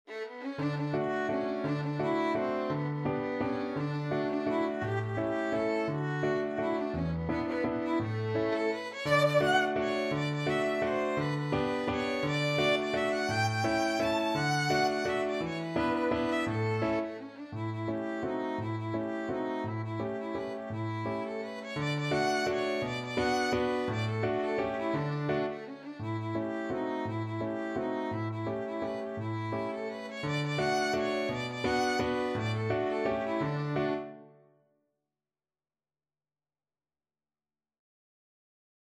3/4 (View more 3/4 Music)
Classical (View more Classical Violin Music)